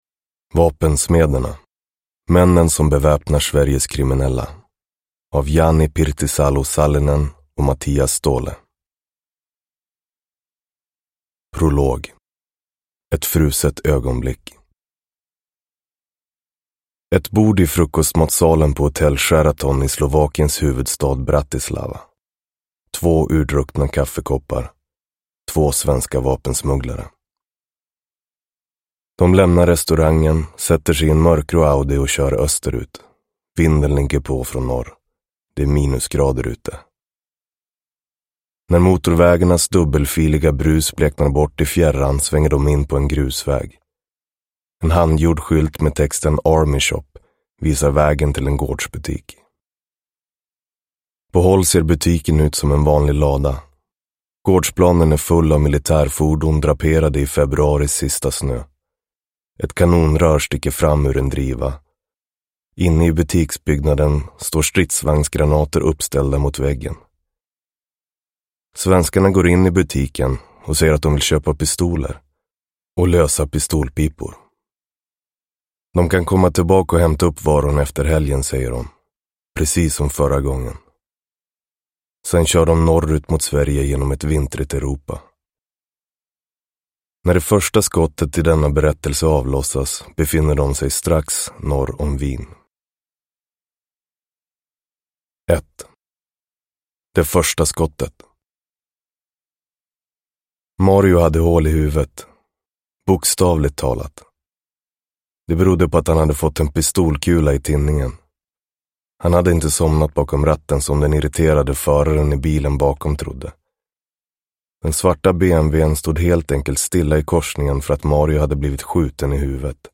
Vapensmederna : männen som beväpnar Sveriges kriminella – Ljudbok – Laddas ner